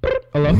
Vox
Brrt Hello.wav